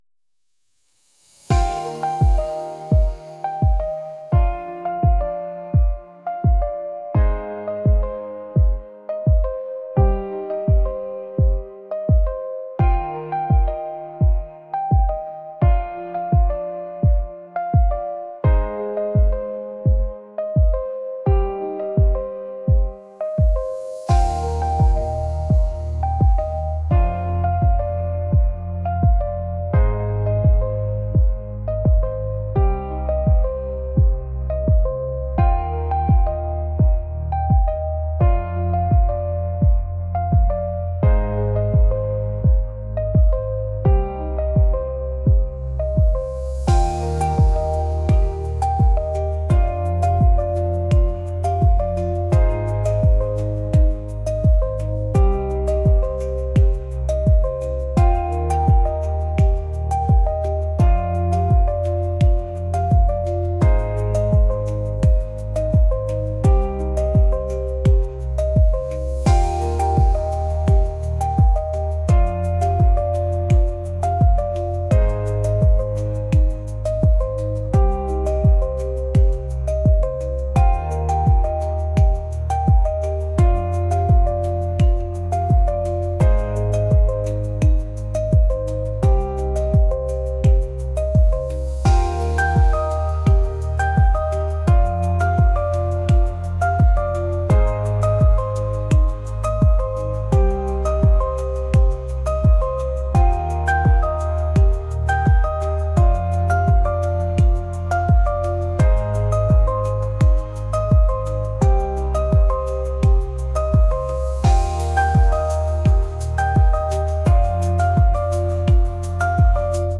pop | indie | lofi & chill beats